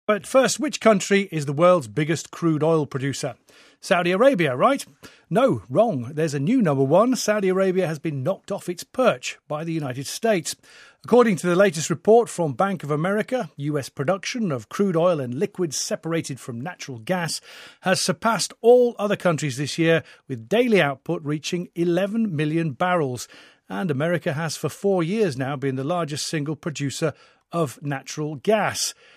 【英音模仿秀】美国原油产量超沙特阿拉伯 听力文件下载—在线英语听力室